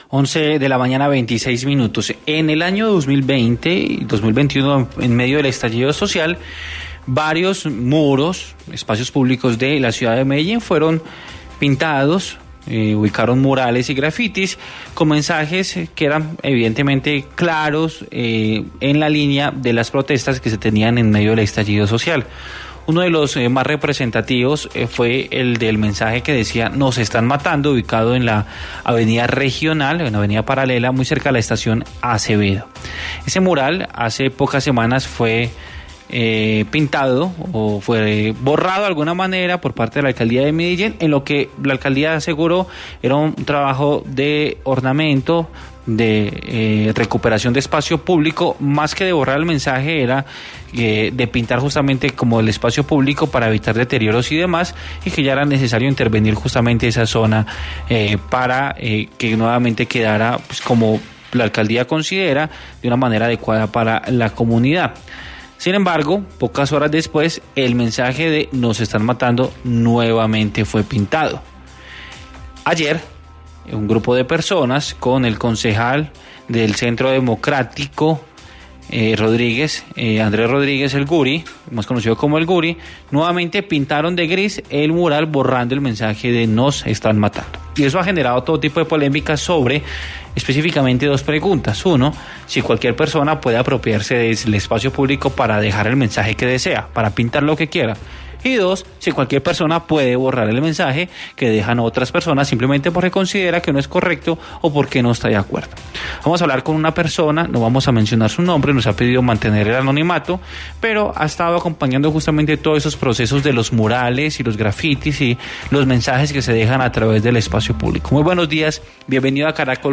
ENTREVISTA_MURAL_28513_cut.mp3